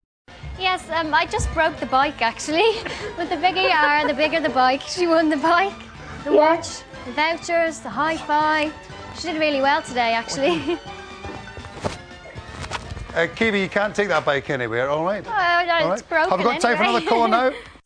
Keavy appeared as a guest presenter on ITV1`s show This Morning.
Some clips have been edited to remove dead air. All crackling/rustling is from the microphones rubbing on clothing.